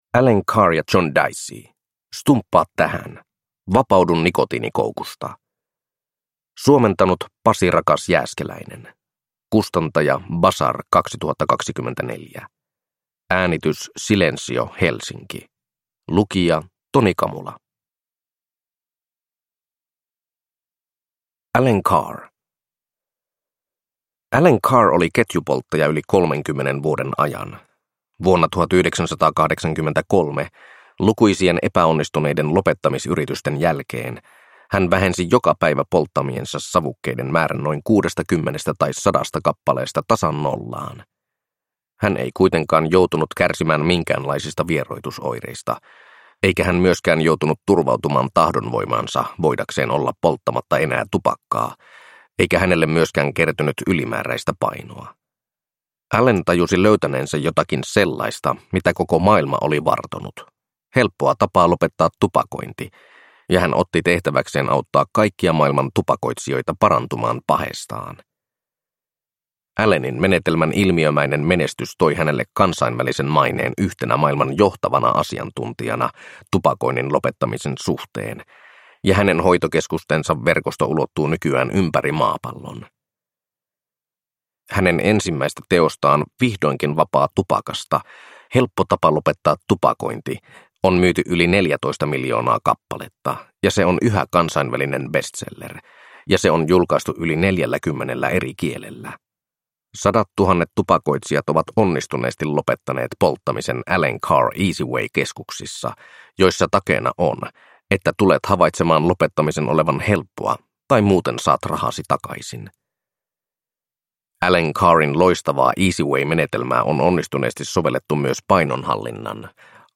Stumppaa tähän! (ljudbok) av Allen Carr